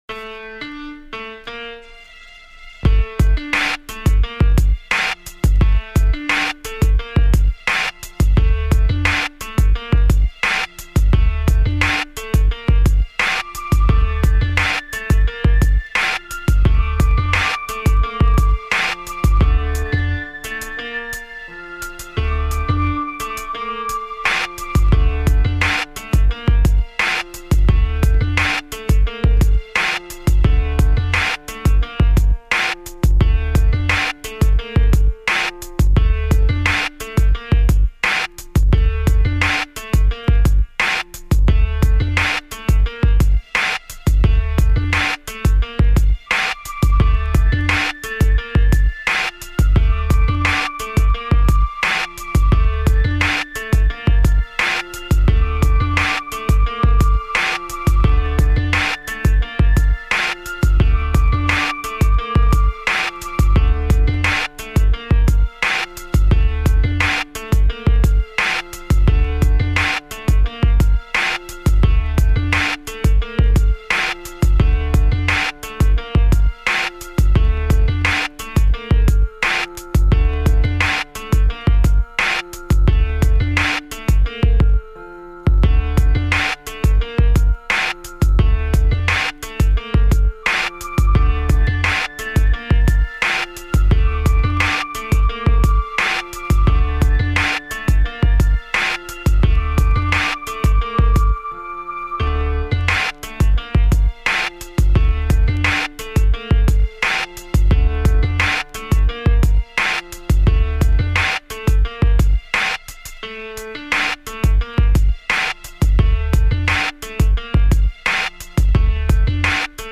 Категория: Instrumentals